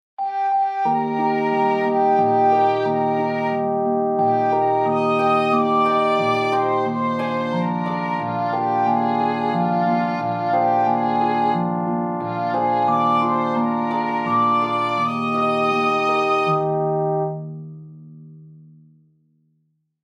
Смирувачките класични мелодии и прекрасните илустрации во оваа книга се совршен начин за смирување на малите бебиња и деца.
baby-bedtime-music-brahms.mp3